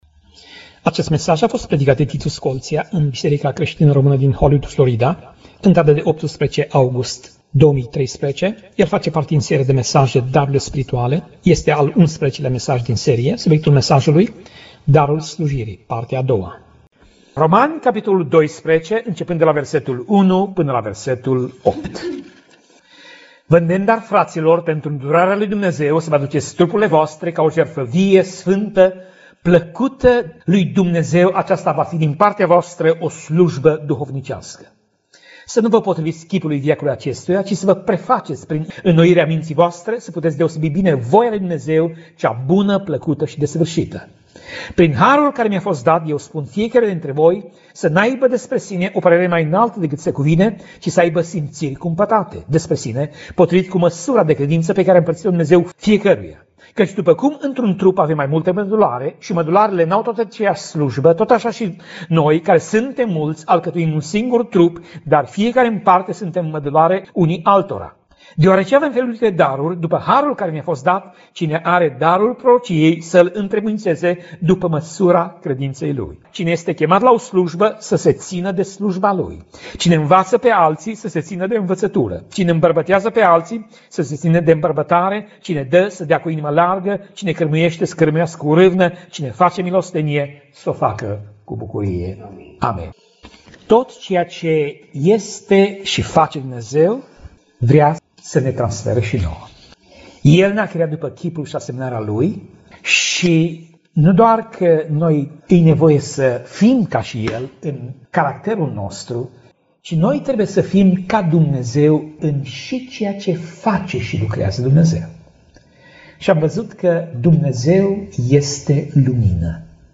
Pasaj Biblie: Romani 12:1 - Romani 12:8 Tip Mesaj: Predica